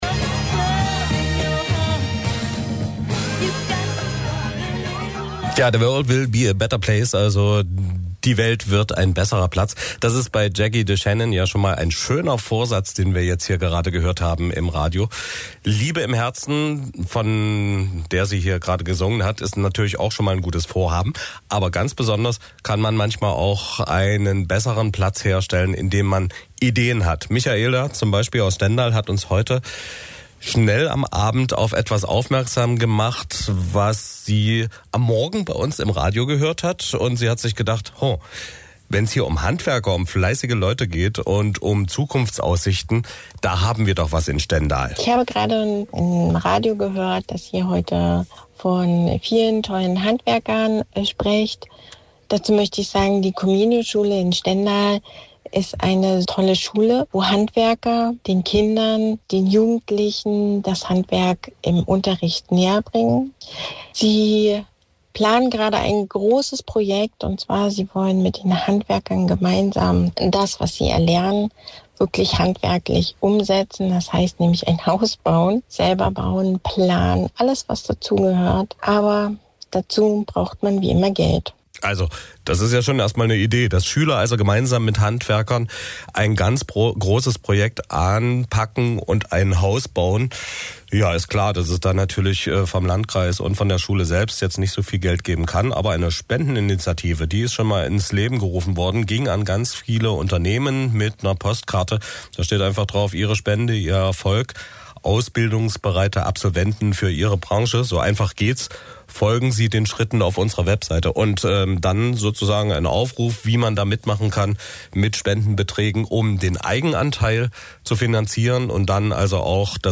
Radiobeitrag MDR_Sachsen-Anhalt (26.03.25).mp3 (3.21 MB)